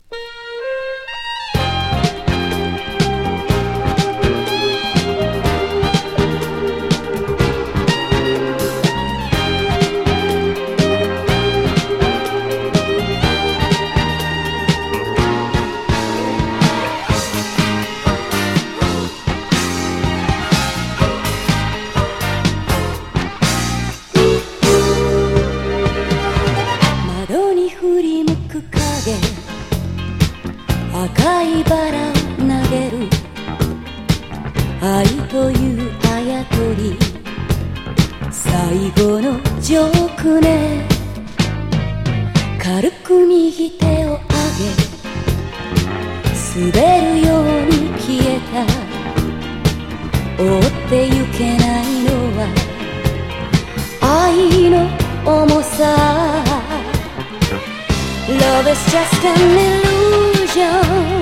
Japanese,Groove,Disco,Vocal ♪LISTEN LABEL/CAT.NUMBER